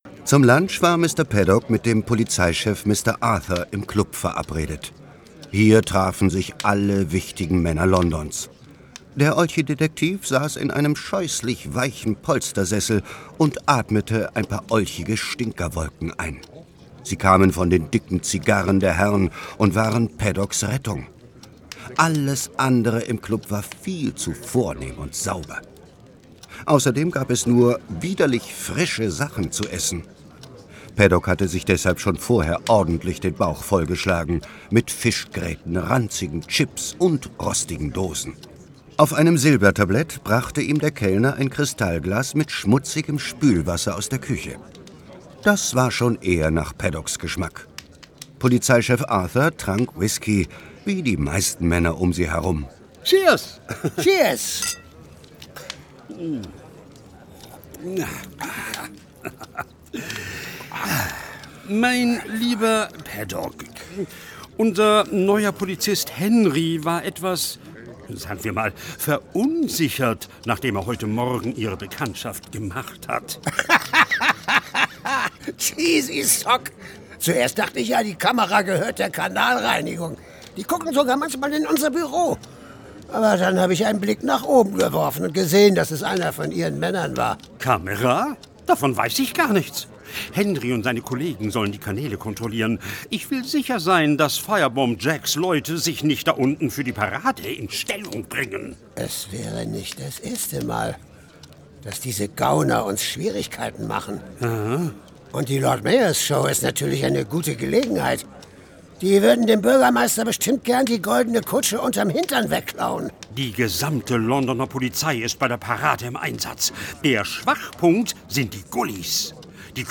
Olchi_Detektive_1-Hoerprobe.mp3